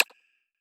sliderbar-notch.wav